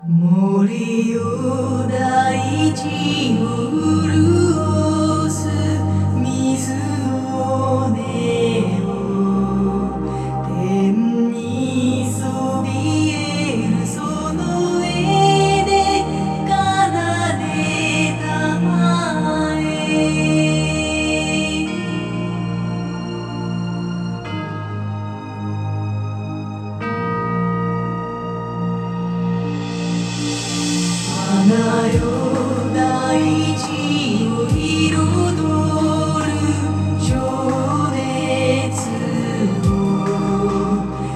そして最後に試してみたのも、いつもリニアPCMレコーダーで実験しているCDの再生音を捉えるというもの。これも96kHz/32bitフロートで録音したものを、過去のほかのケースと比較できるように48kHz/24bitに変換したものだ。
録音サンプル「音楽」
また音を聴くと、これまでで最高ではないか、と感じる高音質なものになっている。